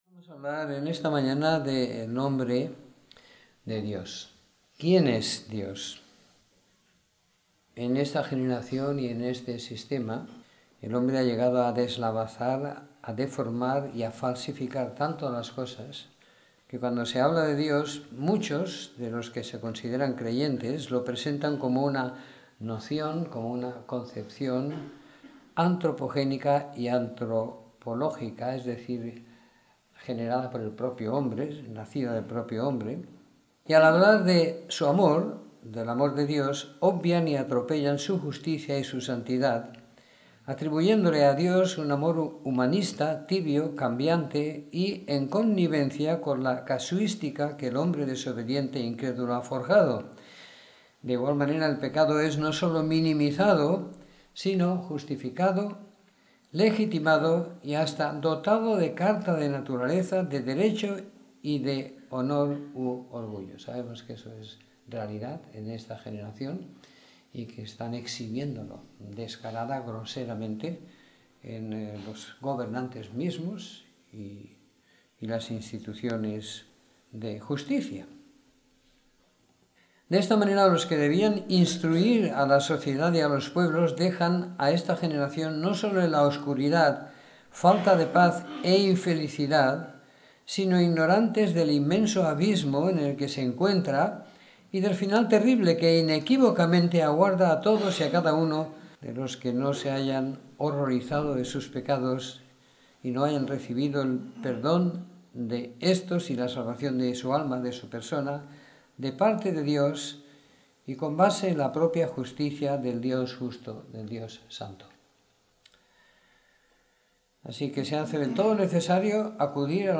Domingo por la Mañana . 18 de Diciembre de 2016